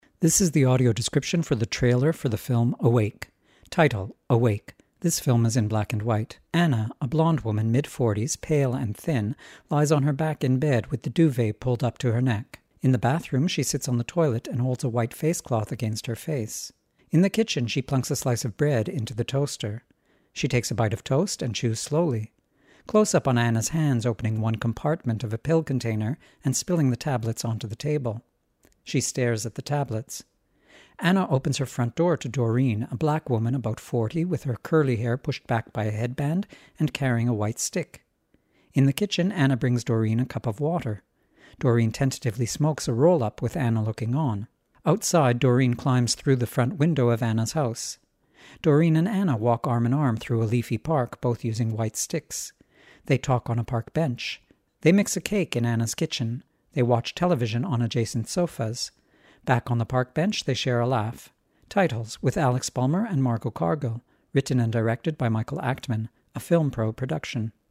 Audio description of trailer:
awake-trailer-ad-rev.mp3